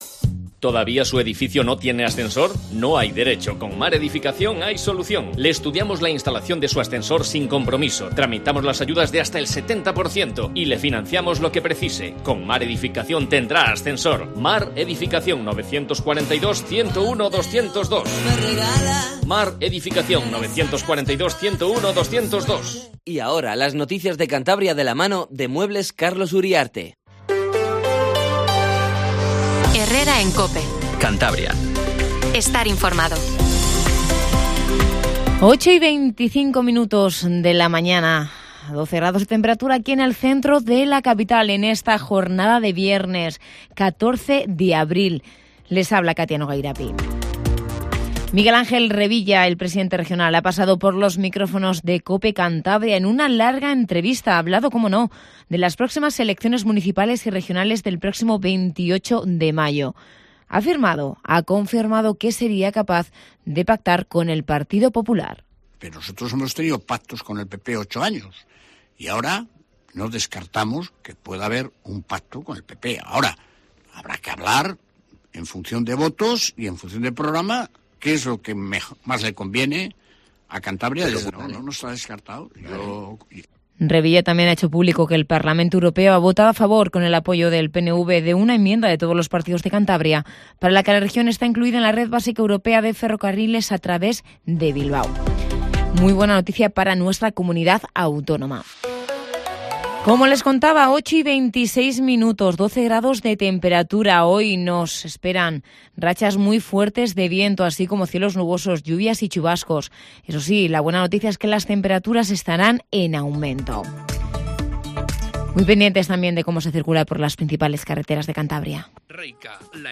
Informativo Matinal COPE CANTABRIA